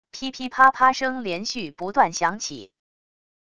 噼噼啪啪声连续不断响起wav音频